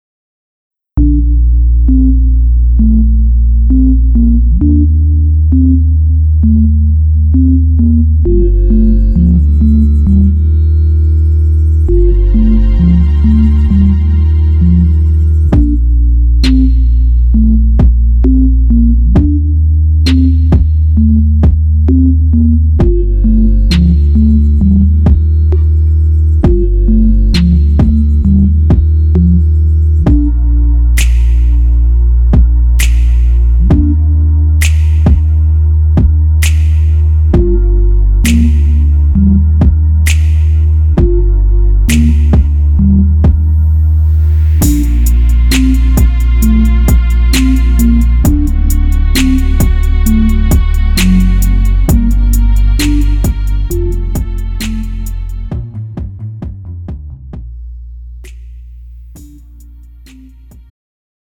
음정 남자키
장르 pop 구분 Pro MR